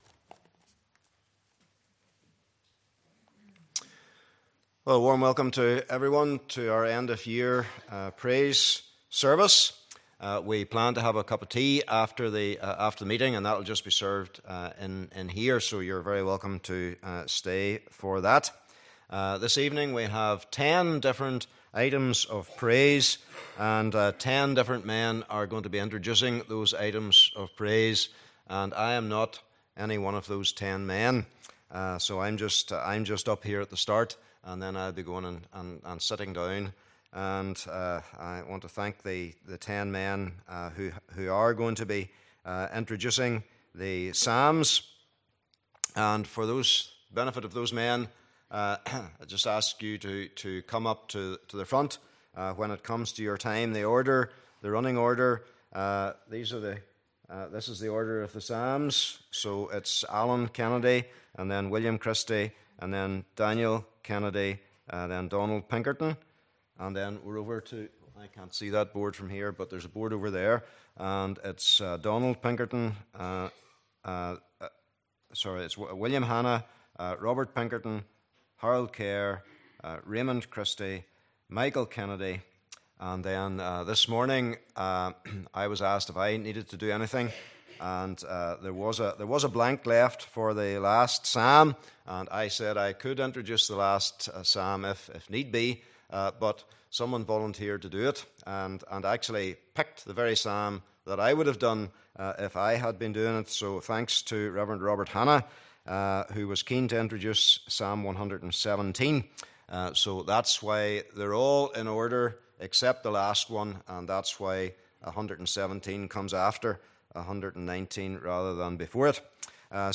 Praise Service December 2019